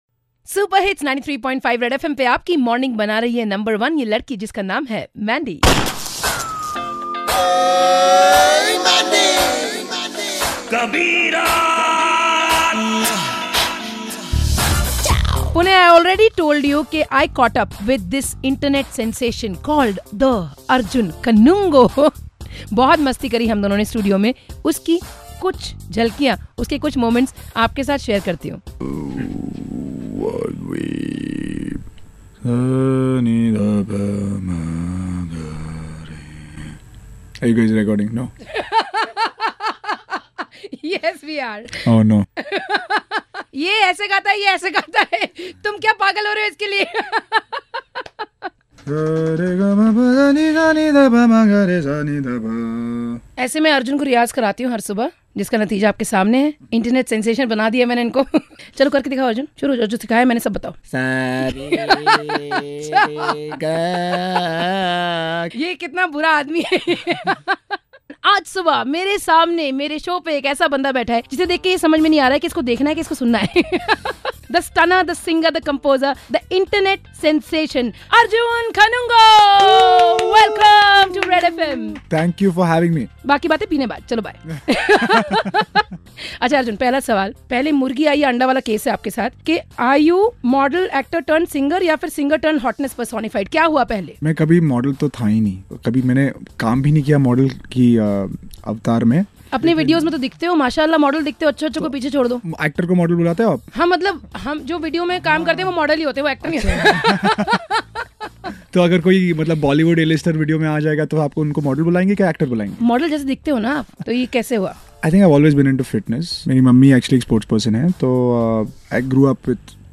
Internet sensation singer Arjun Kanungo aur unki guitar